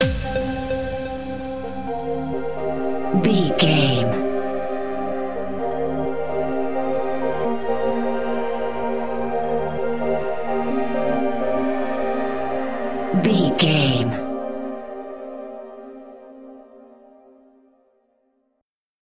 Epic / Action
Fast paced
Aeolian/Minor
aggressive
dark
intense
energetic
driving
synthesiser
drums
drum machine
futuristic
breakbeat
synth leads
synth bass